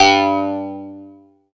69 CLAV G2-R.wav